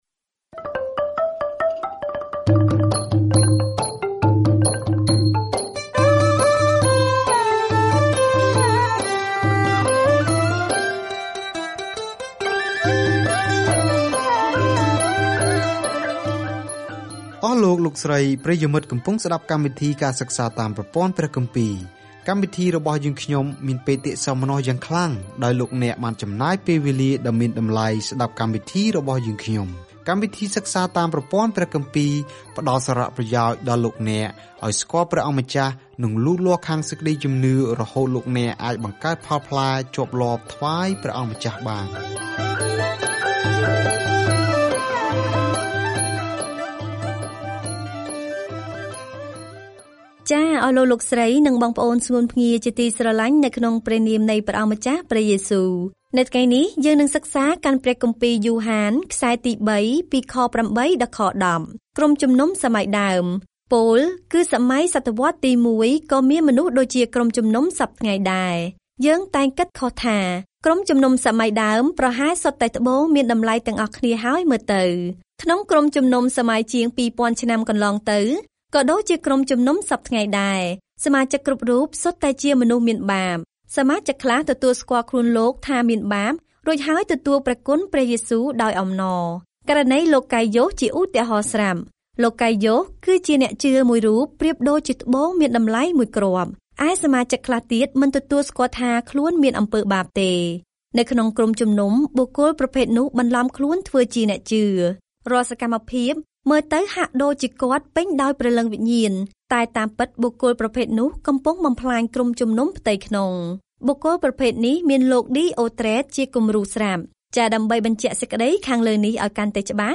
ដើរក្នុងសេចក្ដីពិត ហើយស្រឡាញ់គ្នាទៅវិញទៅមក — នោះជាសារនៃសំបុត្រទីបីពីយ៉ូហានអំពីរបៀបដើម្បីគាំទ្រសេចក្ដីពិត និងដោះស្រាយជាមួយគ្រូមិនពិត។ ការធ្វើដំណើរប្រចាំថ្ងៃតាមរយៈ យ៉ូហានទី 3 នៅពេលអ្នកស្តាប់ការសិក្សាជាសំឡេង ហើយអានខគម្ពីរដែលជ្រើសរើសចេញពីព្រះបន្ទូលរបស់ព្រះ។